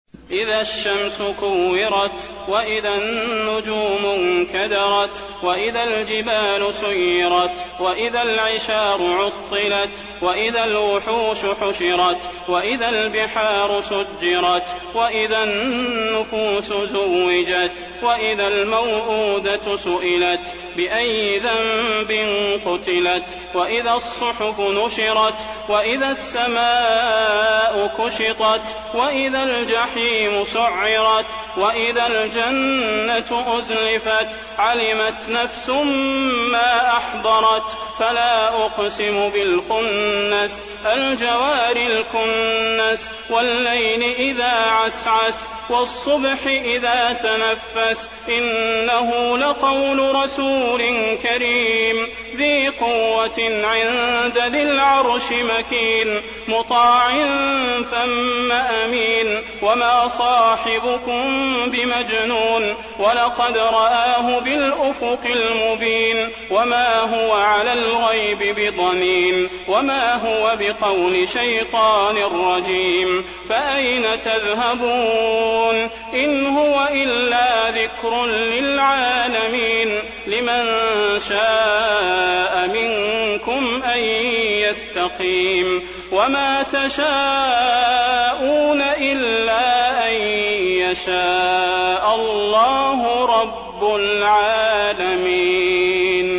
المكان: المسجد النبوي التكوير